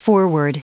Prononciation du mot foreword en anglais (fichier audio)
Prononciation du mot : foreword